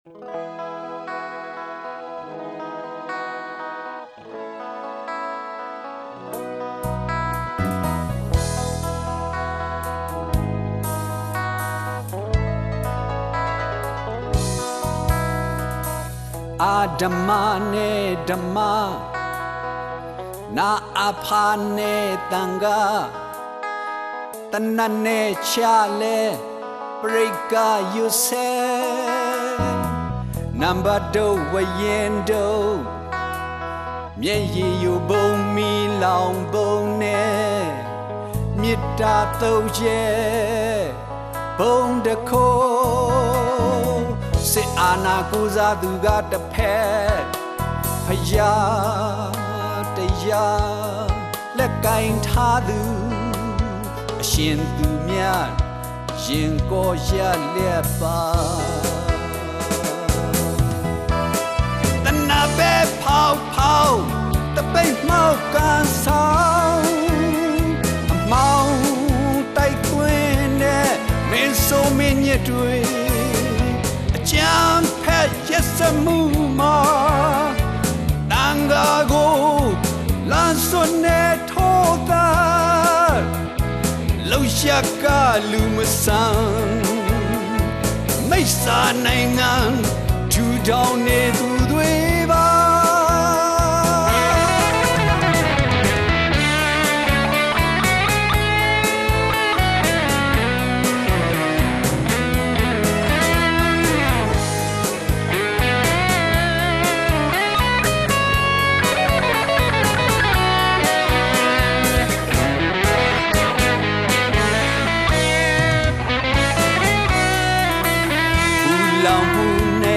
ရှမ်းအဆိုတော်